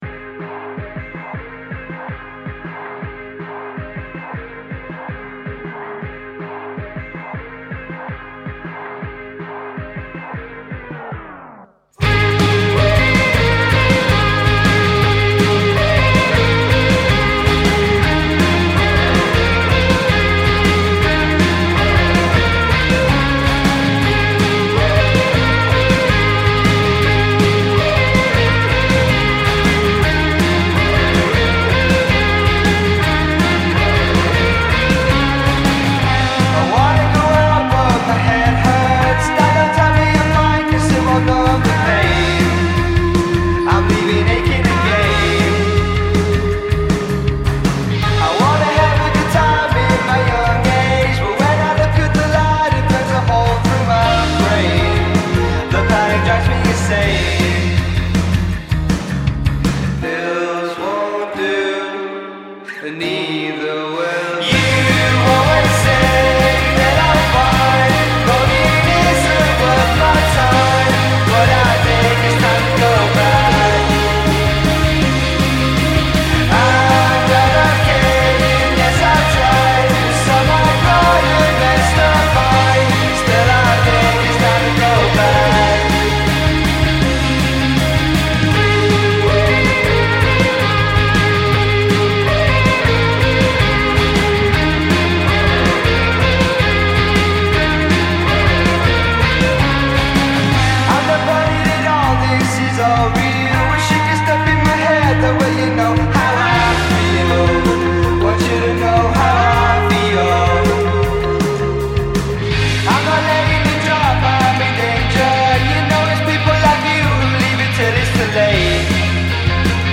Chesterfield (UK) quartet